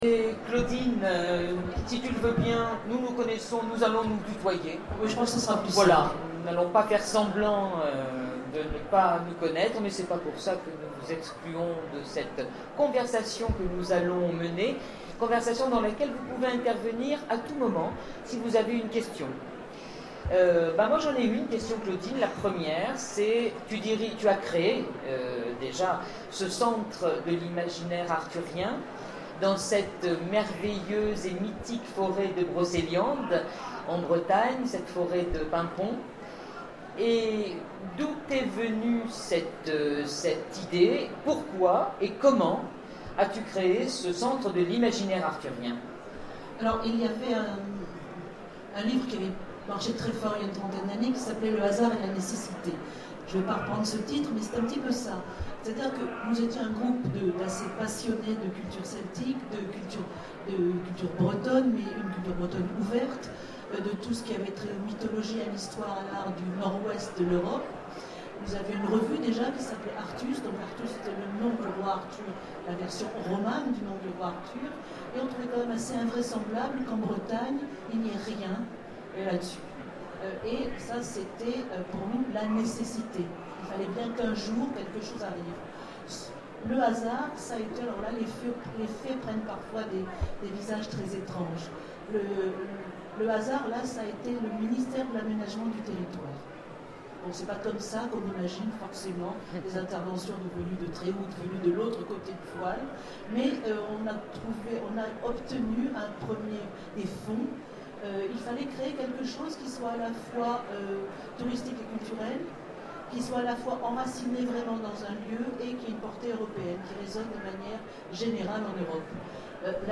Zone Franche 2014 : Conférence La Légende d’Arthur et des chevaliers de la Table Ronde